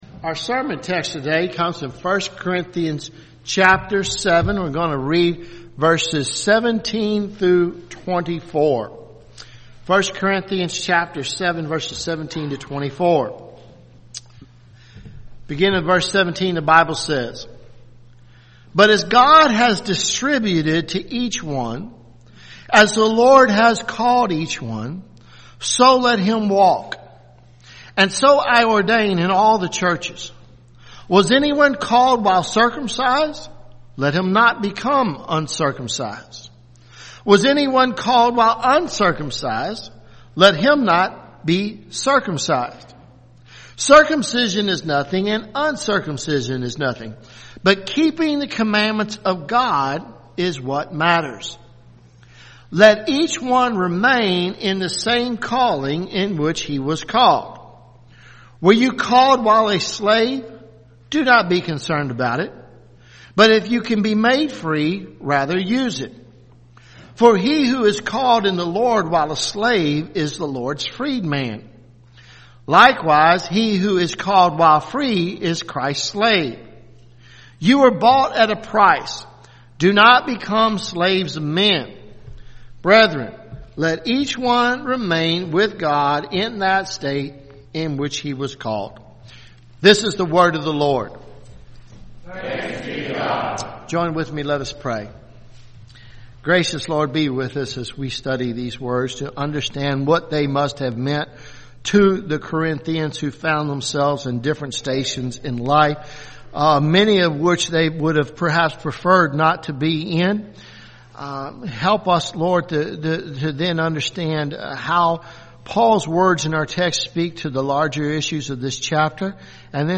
at Christ Covenant Presbyterian Church, Lexington, Ky.
Sermons